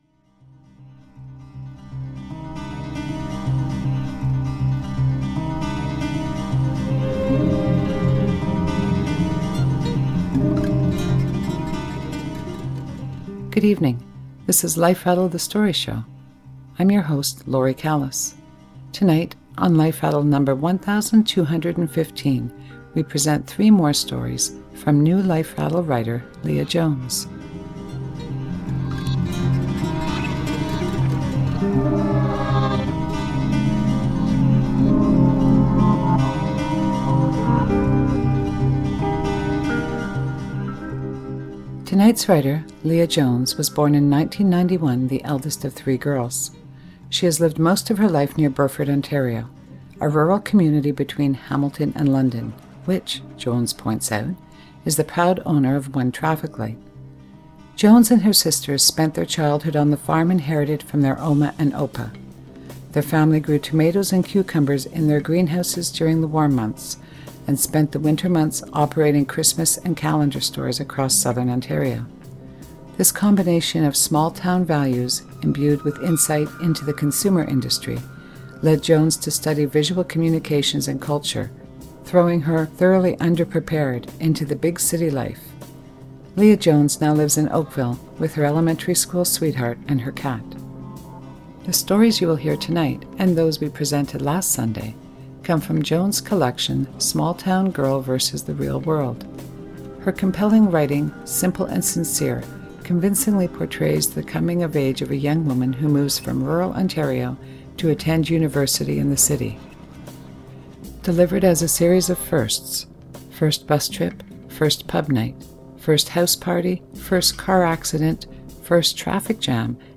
reading her stories